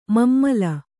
♪ mammala